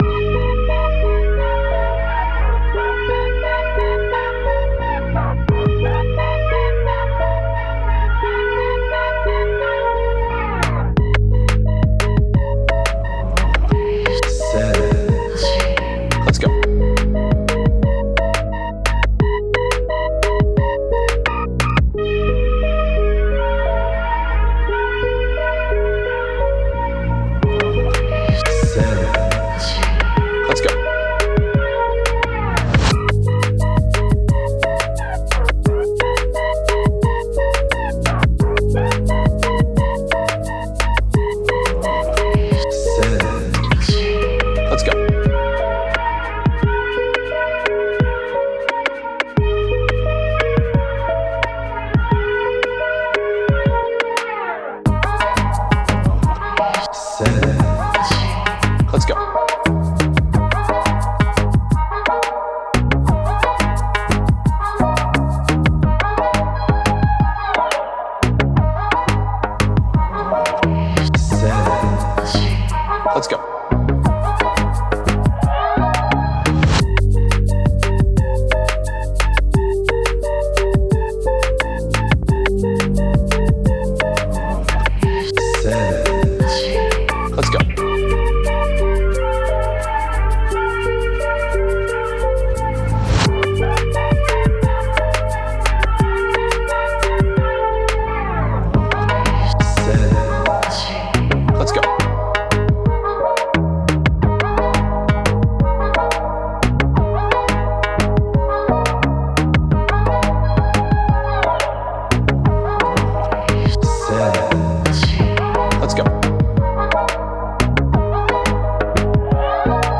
Dark – Reggaeton – Afro Beat – Type Beat
Key: G#m
175 BPM